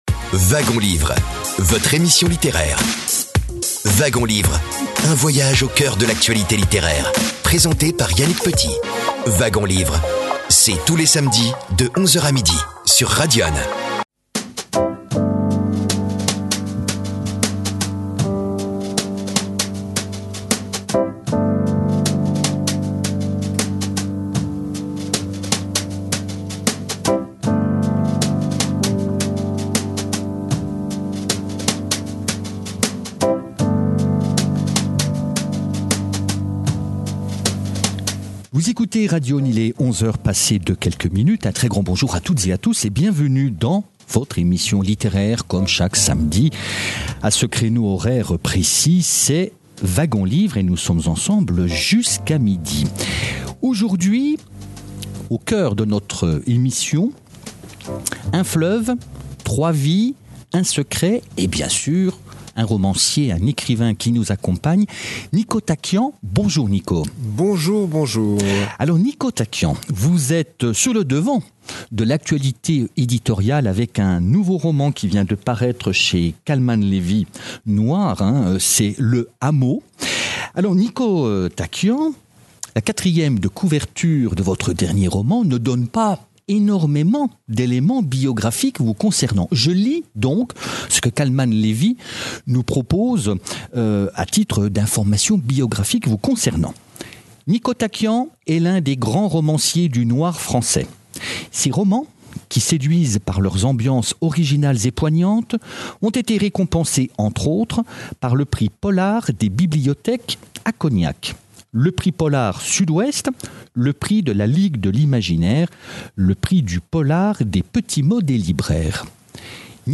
Entretien avec Niko TACKIAN pour son dernier thriller « Le Hameau » (Ed. Calmann-Levy noir), enregistré à Dijon, le 25 mars 2026, alors que le romancier était l’invité du Club des Écrivains de Bourgogne.